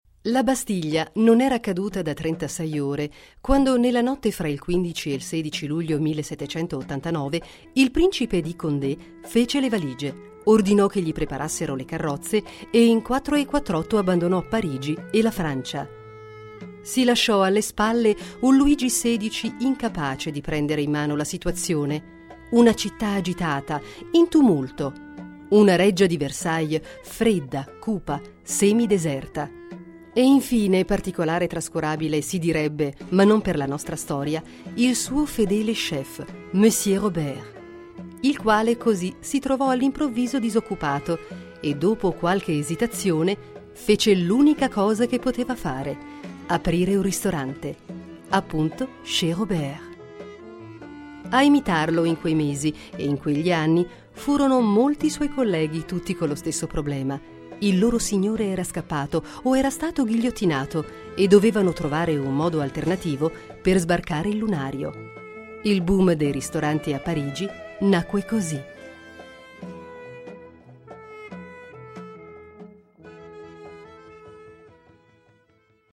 Sprecherin italienisch. voce narrante nazionale per RAI, SKY, audiolibri, audioguide
Sprechprobe: eLearning (Muttersprache):
female italian voice over artist. voce narrante nazionale per RAI, SKY, audiolibri, audioguide